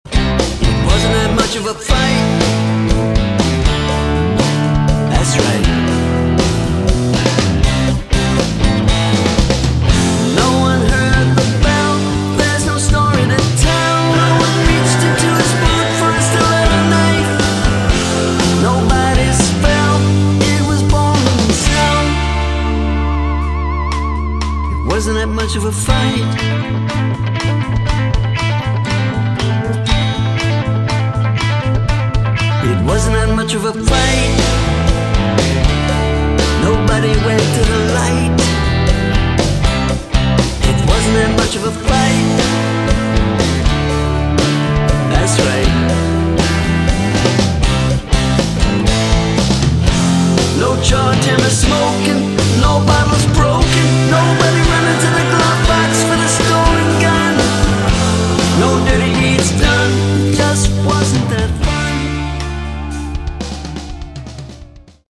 Category: Classic Hard Rock
lead vocals, guitars, keyboards
bass
drums